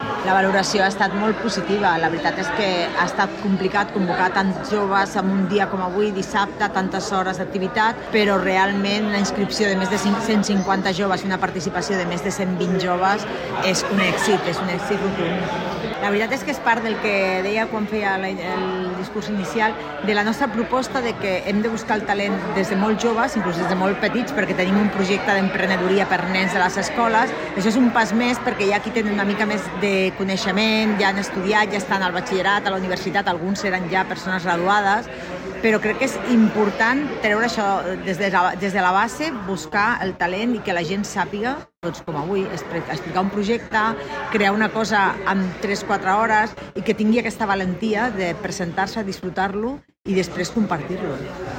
Rosa Cadenas, regidora Promoció Econòmica